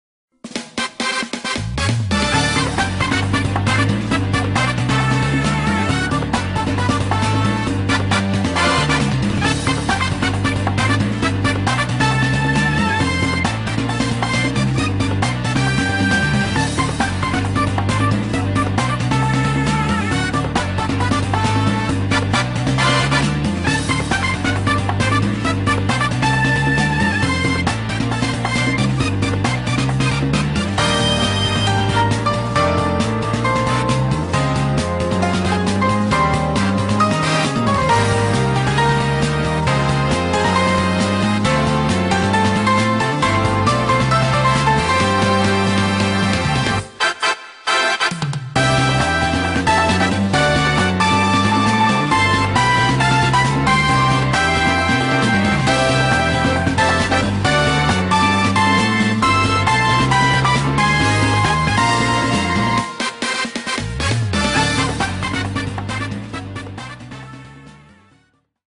BPM135
Audio QualityCut From Video